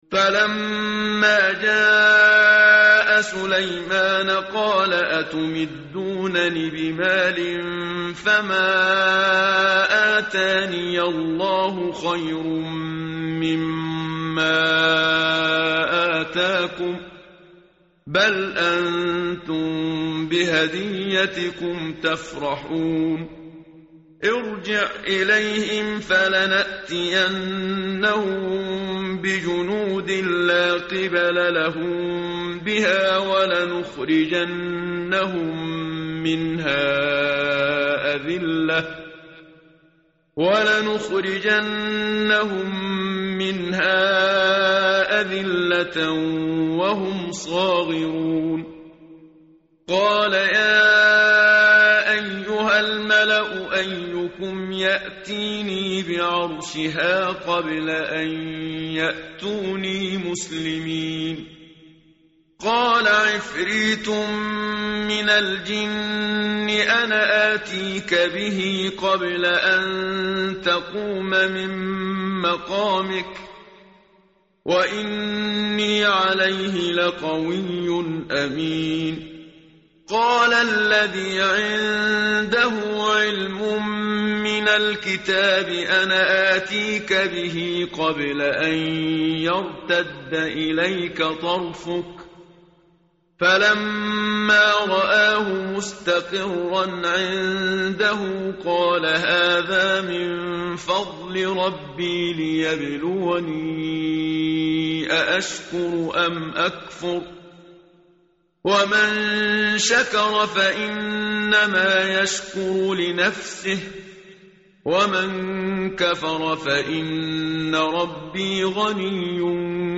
متن قرآن همراه باتلاوت قرآن و ترجمه
tartil_menshavi_page_380.mp3